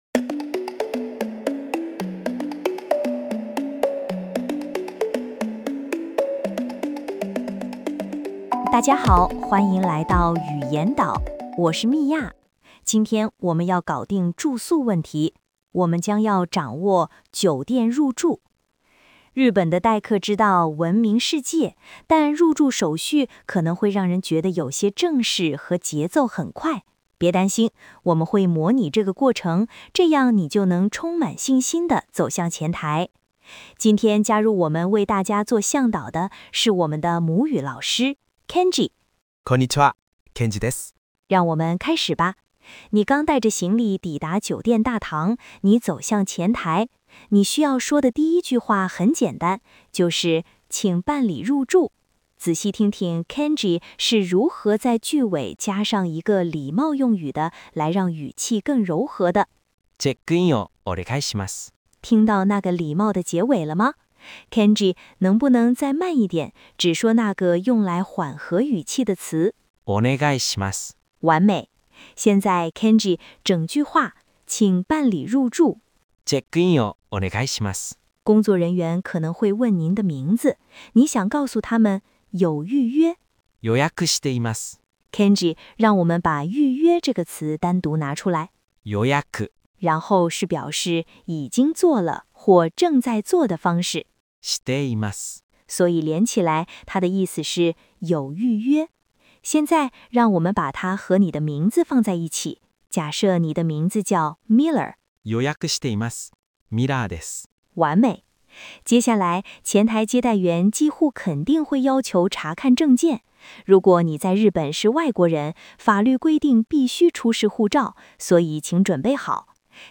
hotel-checkin.mp3